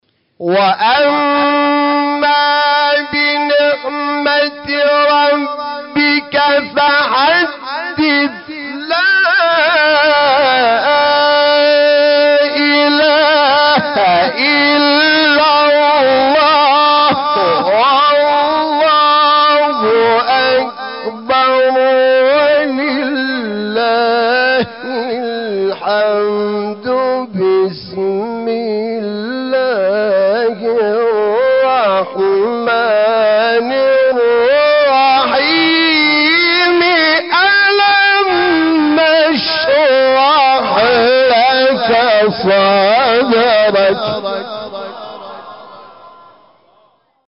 سوره : ضحی – انشراح آیه : 11 – 1 استاد : حامد شاکرنژاد مقام : بیات قبلی بعدی